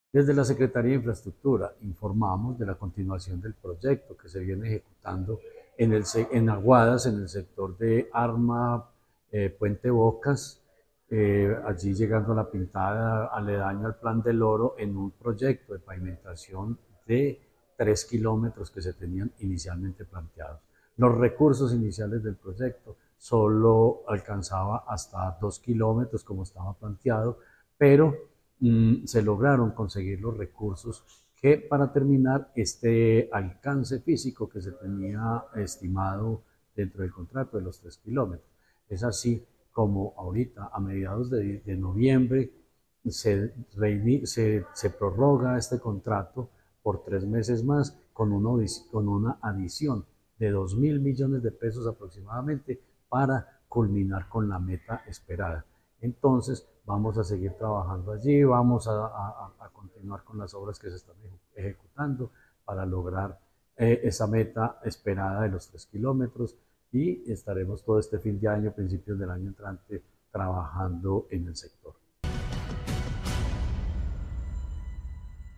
Jorge Ricardo Gutiérrez Cardona, secretario de Infraestructura de Caldas.